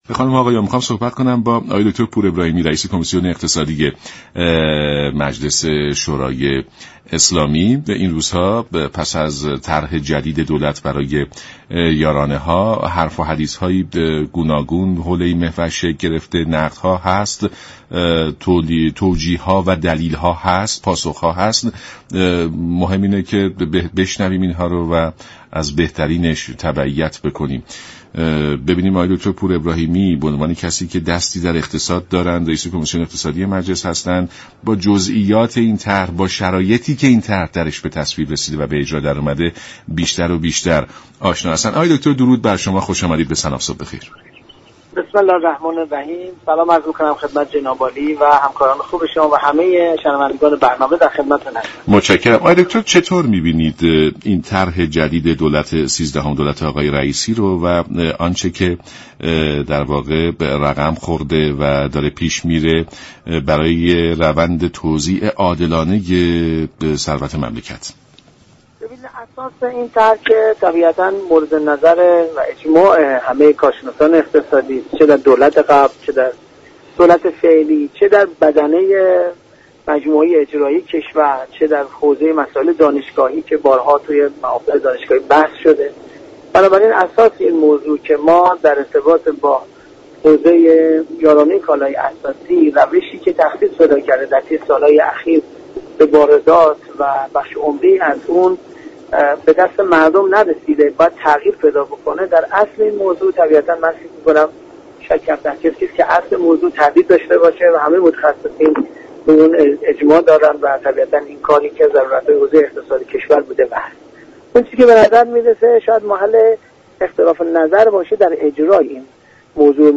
به گزارش شبكه رادیویی ایران، محمدرضا پور ابراهیمی نائب رییس كمیسیون اقتصادی مجلس در برنامه «سلام صبح بخیر» رادیو ایران درباره طرح اصلاح یارانه گفت: در كلیات اصل اصلاح نظام پرداخت یارانه‌ها، همه اعم از متخصصان، دانشگاهیان، مجموعه دولت فعلی و پیشین موافق هستند و اصل اختلاف ها در نحوه اجرا است.